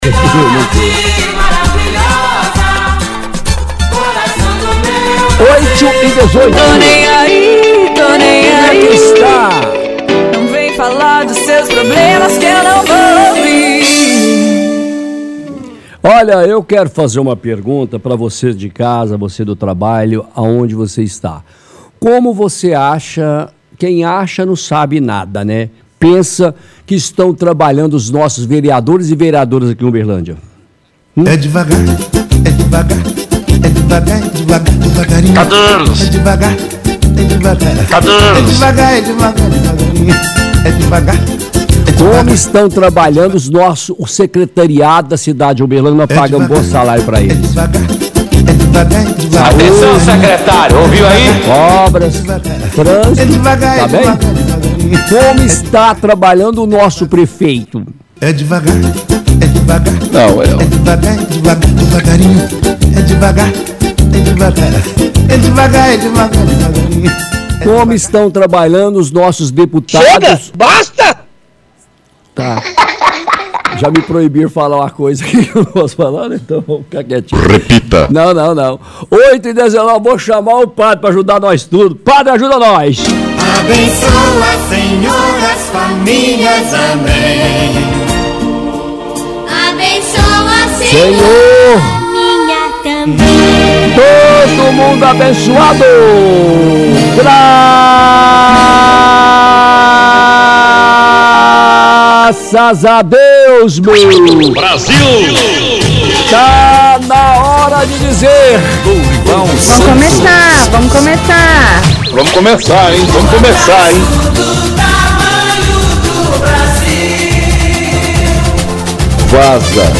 (Roda música é Devagarinho).